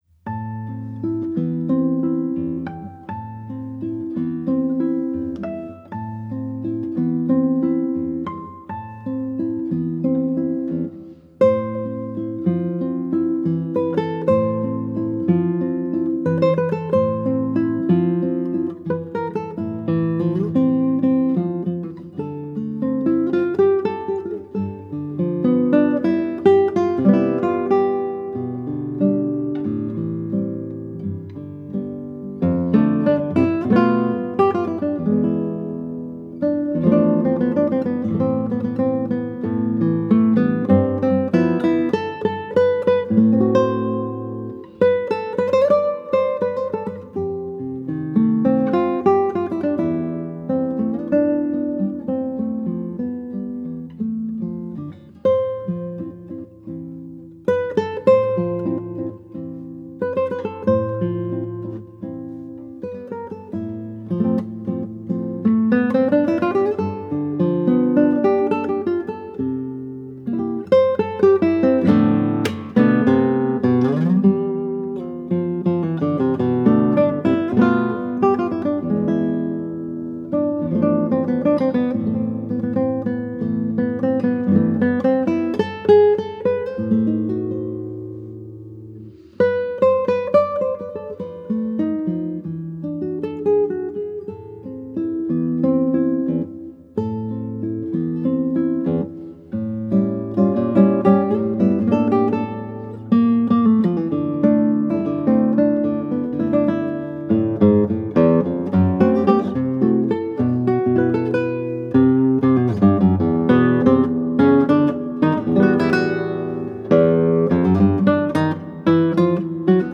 Daar zorgt de klank van de gitaar voor.